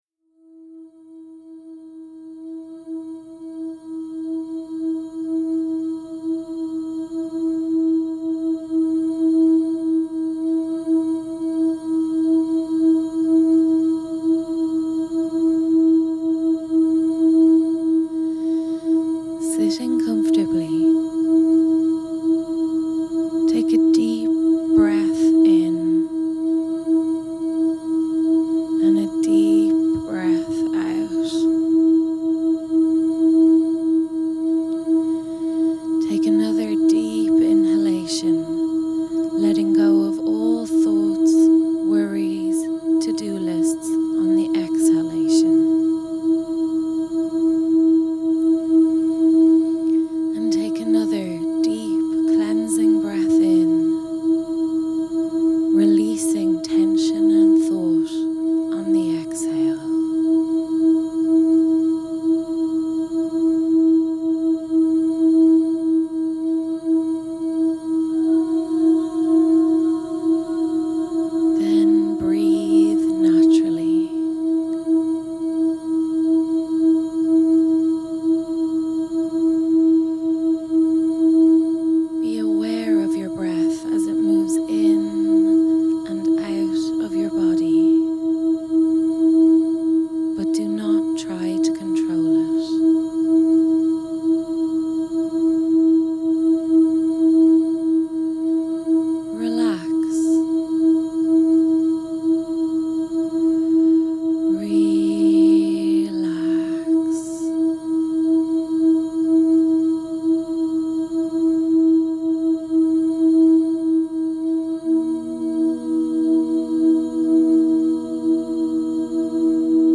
Here is a short guided meditation for that purpose- it can of course be used for those you know and love too but perhaps for today, you might think of doing it in honour of all those who are feeling alone.
heart-meditation_christmas_mastered.mp3